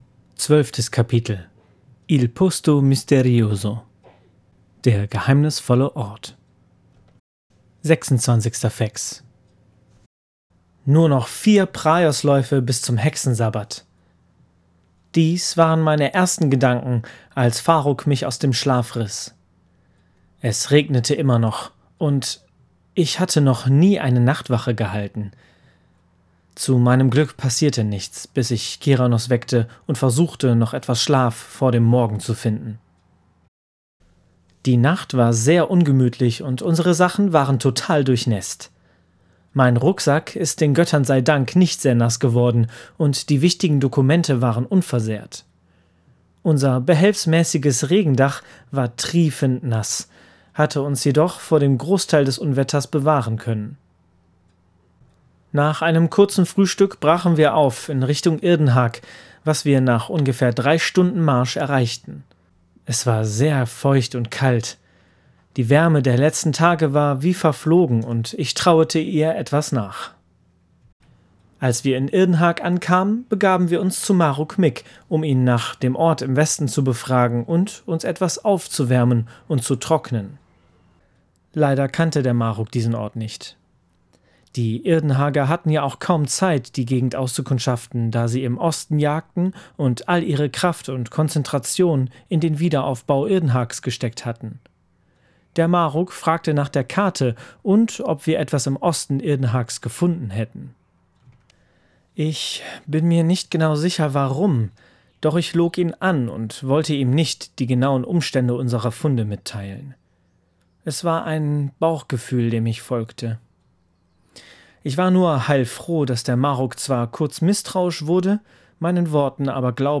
Die Calleano-Chroniken – Ein Hörbuch aus der Welt des schwarzen Auges